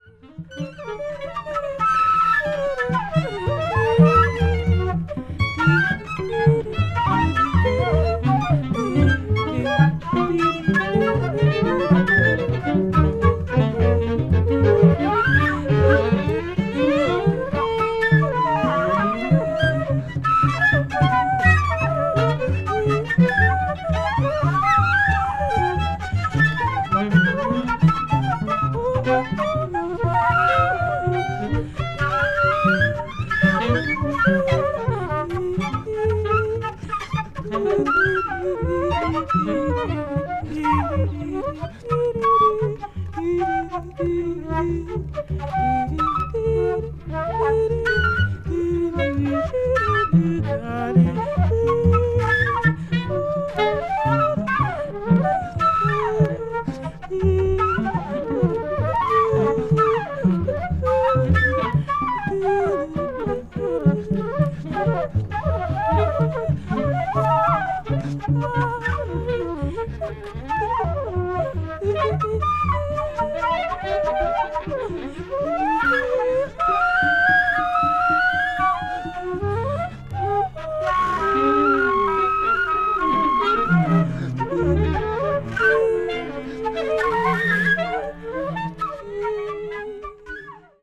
avant-jazz   free improvisaton   free jazz